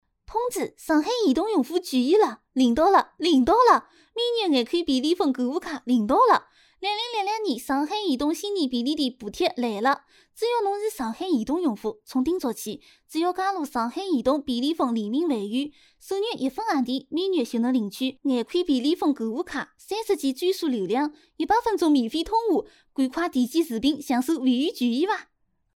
上海移动广告